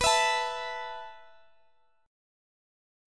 B7sus4 Chord
Listen to B7sus4 strummed